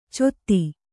♪ cotti